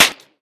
light_crack_08.ogg